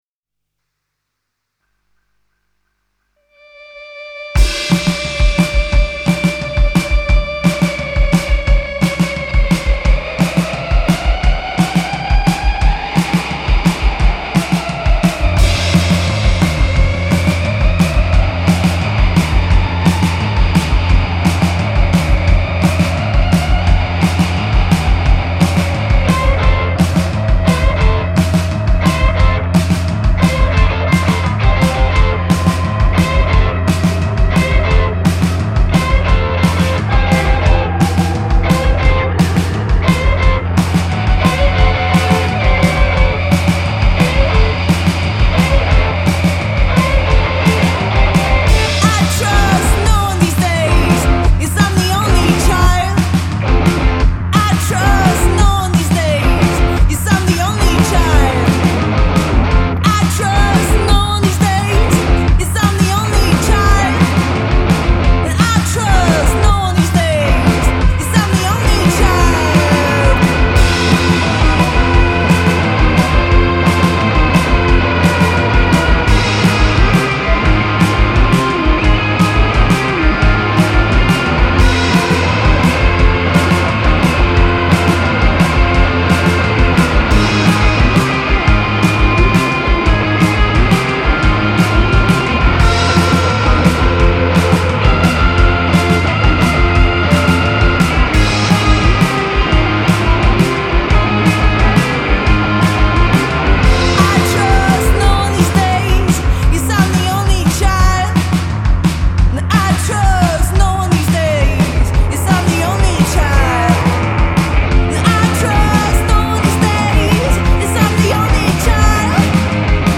gitara
perkusja.